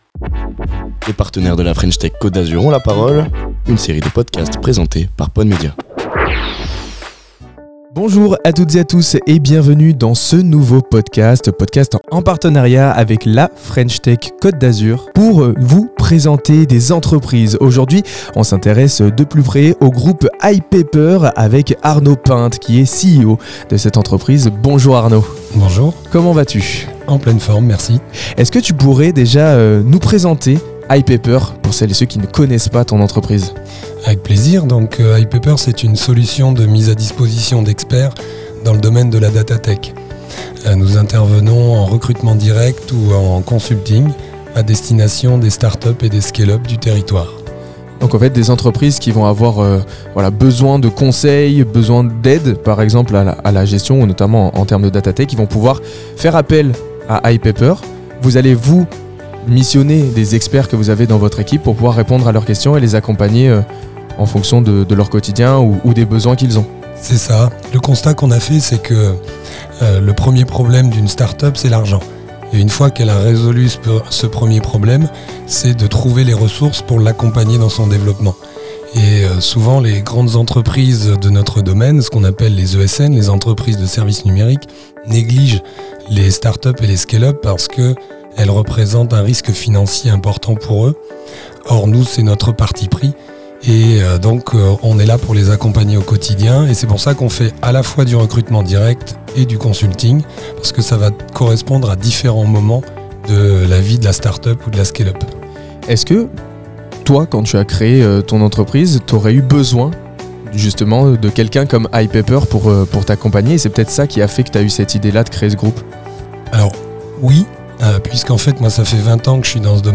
Interview réalisée par PodMedias, merci à toute leur équipe !